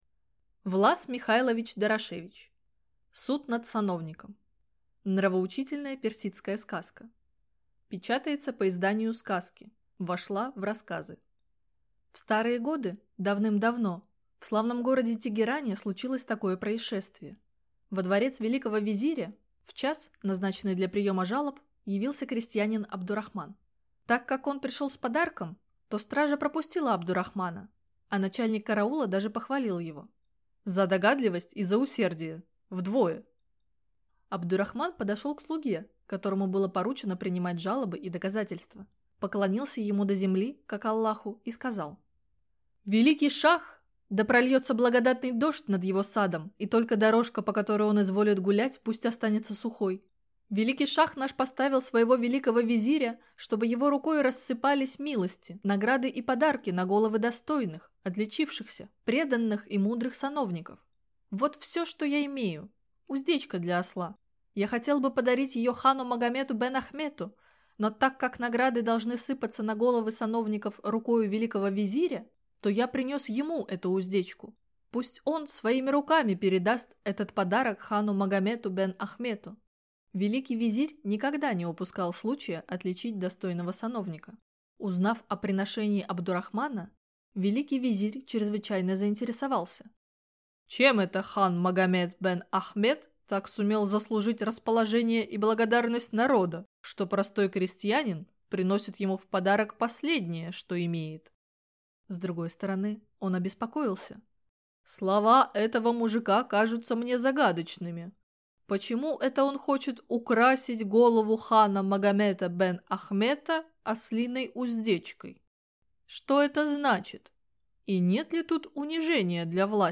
Аудиокнига Суд над сановником | Библиотека аудиокниг